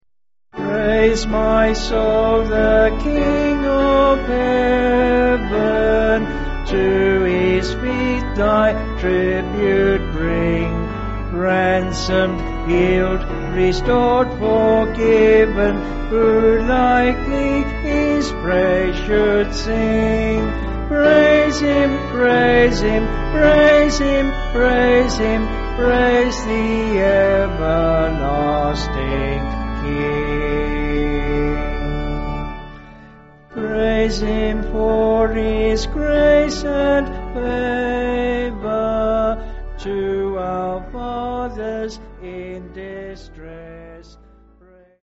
Vocals and Piano/Organ